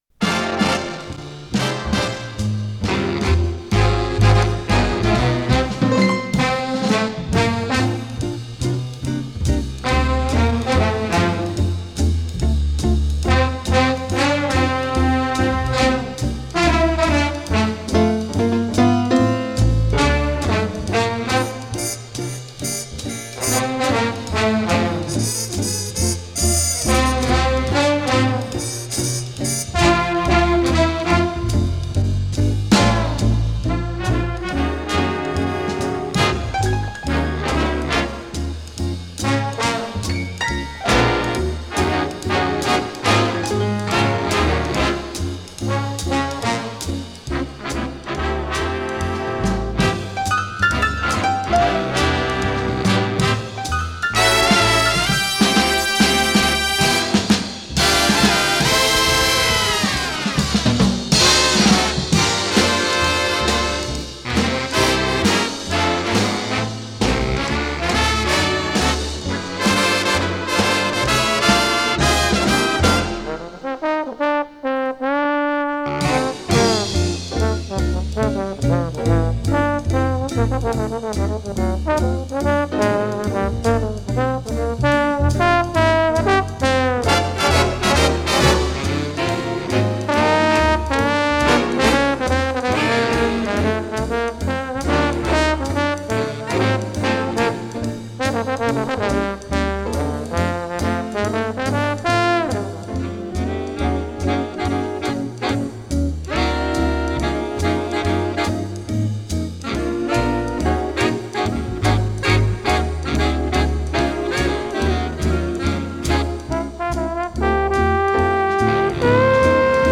с профессиональной магнитной ленты
ПодзаголовокПьеса для эстрадного оркестра, си бемоль мажор
тромбон
контрабас